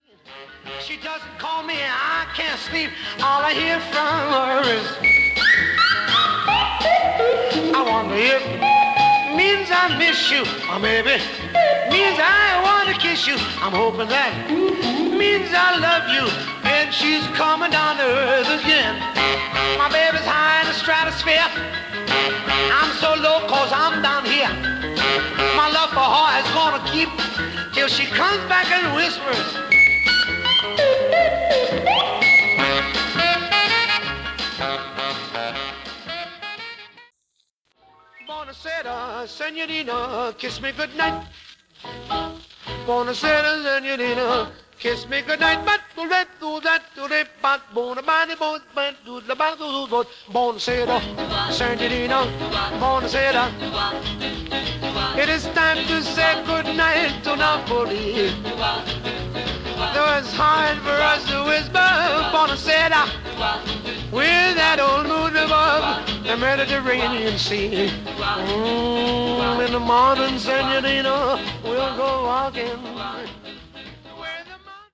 Genre: Jive/Swing/Jazz
Song(s) sample clip here was made from THIS copy!